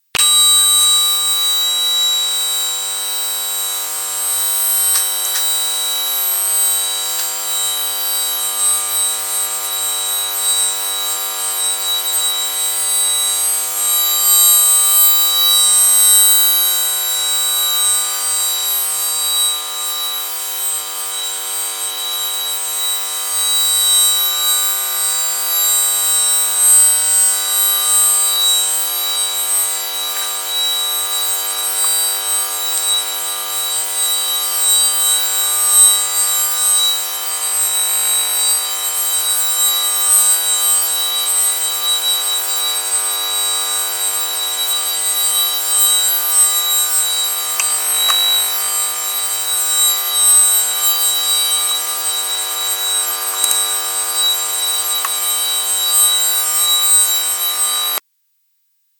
Recording of the standing wave using a parabolic microphone.
ultrasound standing wave recording
This is the analysis of the recording above and you can see the peaks and troughs of the standing wave.
ultrasound-standing-wave-mp3.mp3